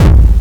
Took a standard RYTM kick drum, ran it through Heat and printed it to cassette.
I played the kick at different speeds from the cassette into AudioShare (love that app!).
Single kick;